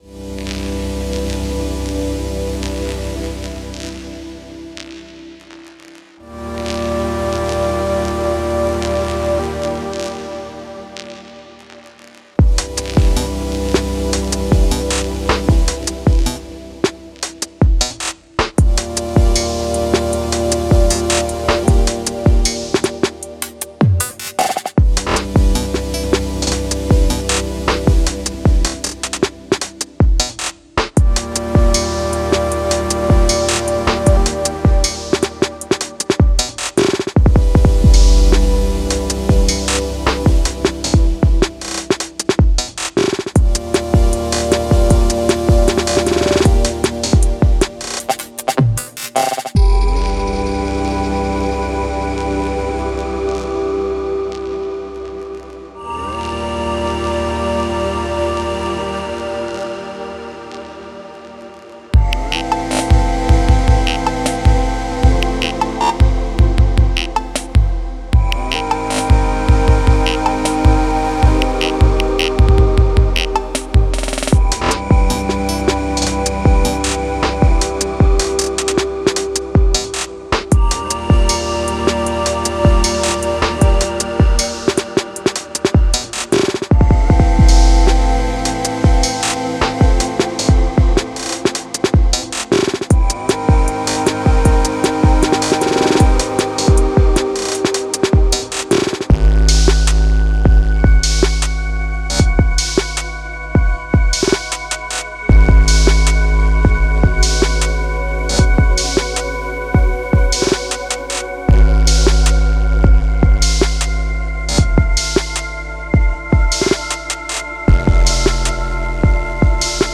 something from yesterday ! this morning mixed a bit to control some of the resonances besides that its a kinda simple tune clicks&cuts ish exploratory idm ish inspired
clicks _changes.wav